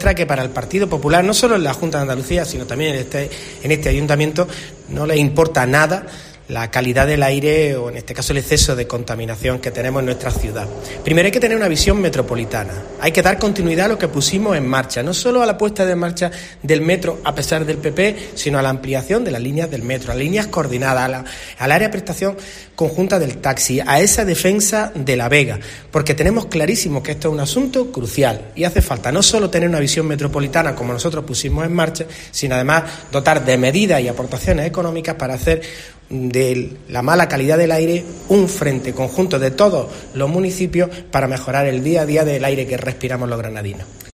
Paco Cuenca, portavoz del PSOE en el Ayuntamiento